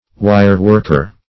Wire-worker \Wire"-work`er\, n. One who manufactures articles from wire.